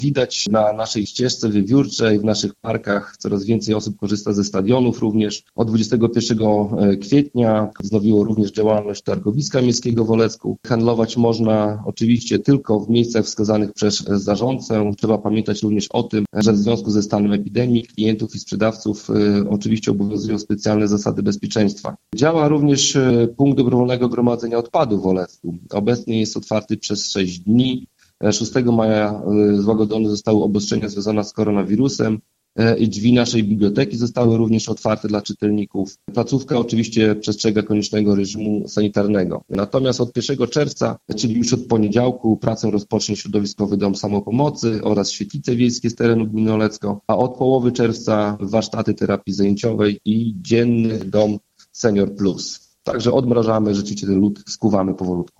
O aktualnej sytuacji w mieście rozmawialiśmy z Karolem Sobczakiem, burmistrzem Olecka.